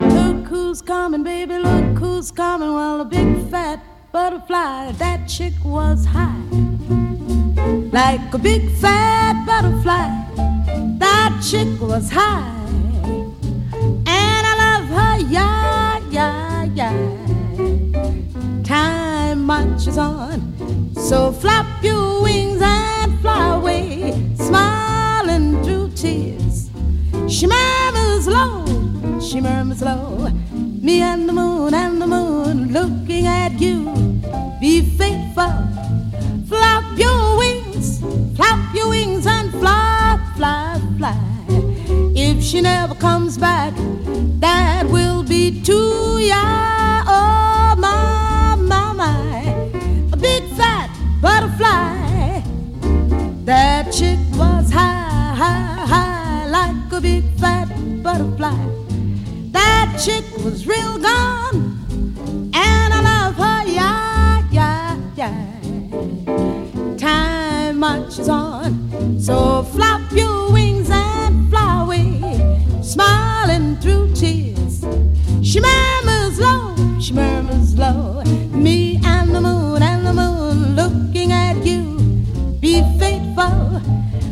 JAZZ / JAZZ VOCAL
女性ジャズ・ヴォーカル・ファン必聴！
でのスロー・ブルース
での包み込むような低音ヴォイスまで、聴きどころ満載のヴォーカル・アルバムです。